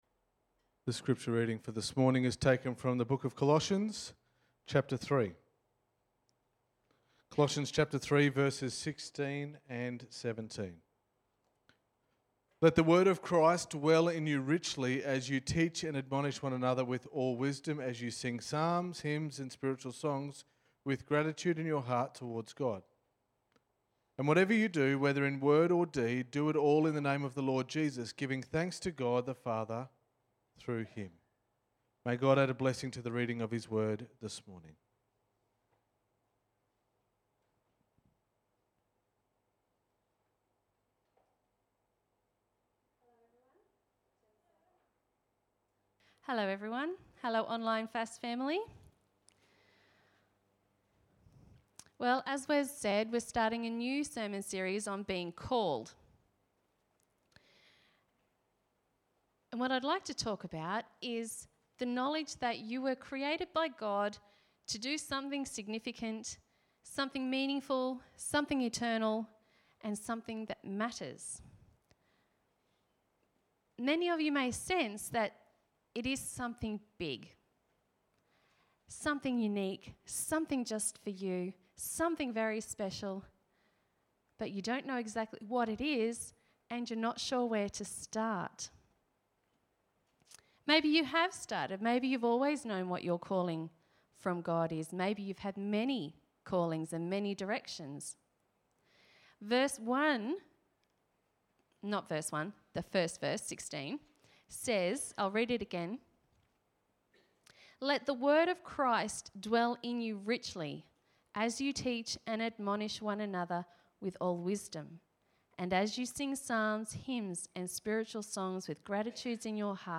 Sermon 01.11.2020